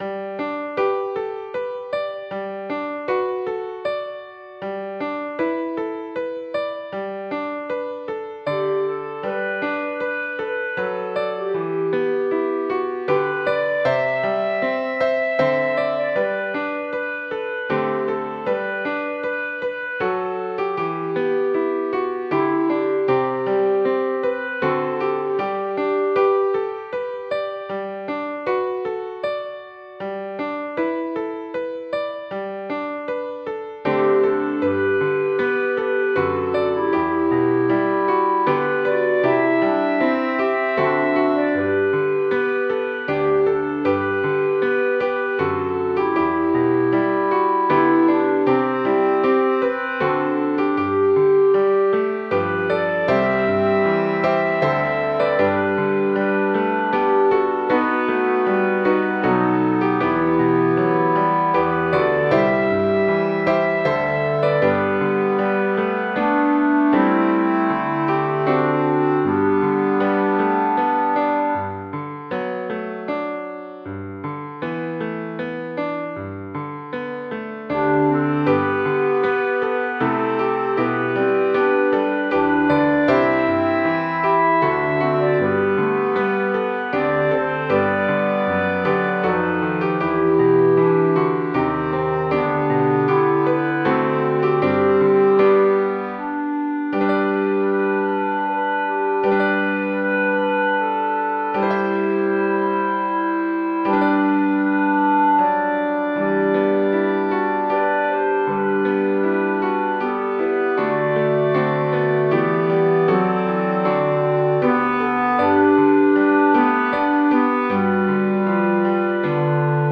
「合唱」種類數位樂譜，最低訂購數量為20份，樂譜單價將以團體價80元/份計。